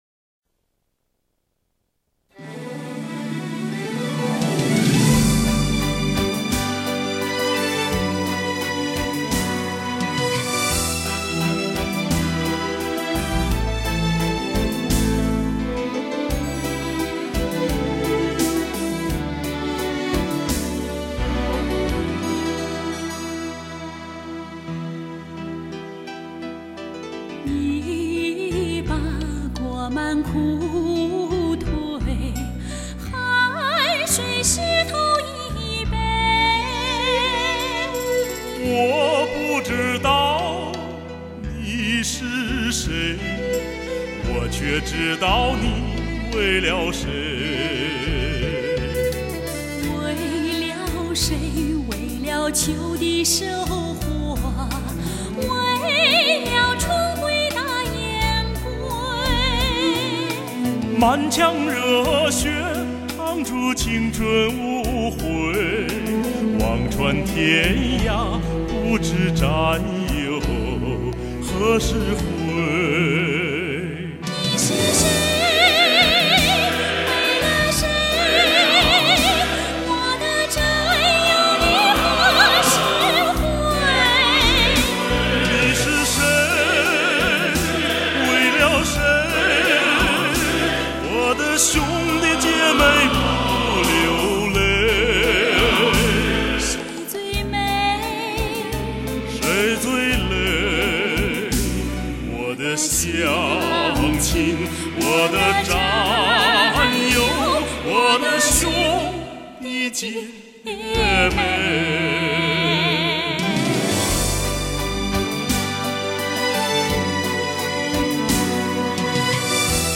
民族风格的旋律和当代通俗歌曲的节奏的结合形成了他那独特的创作风格。